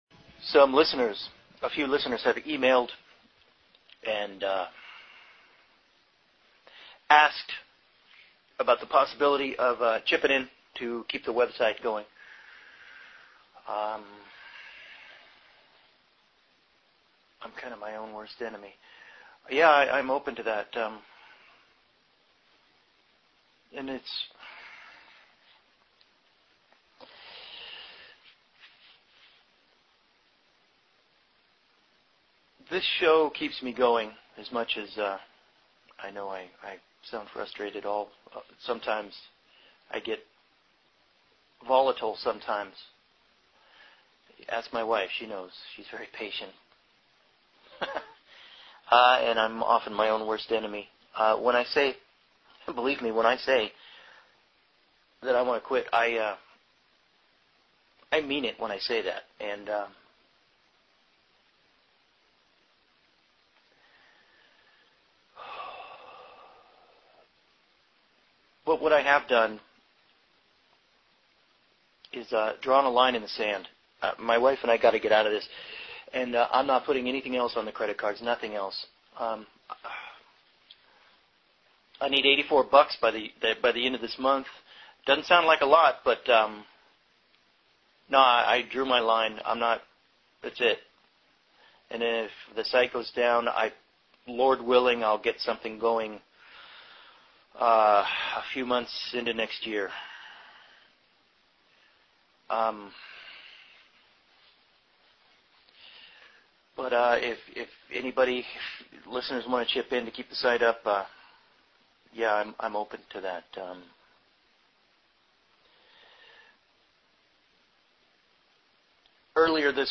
Listen to the essay read